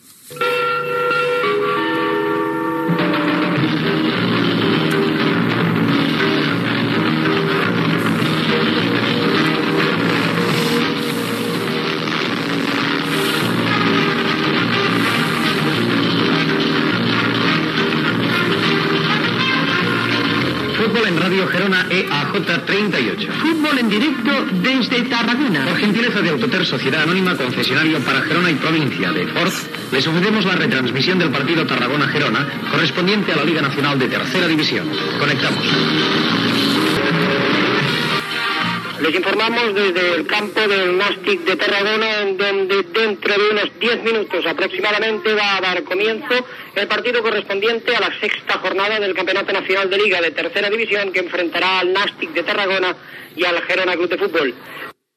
Careta del programa i inici de la transmissió del partit de futbol masculí de Tercera Divisió entre el Nàstic de Tarragona i el Girona Club de Fútbol
Esportiu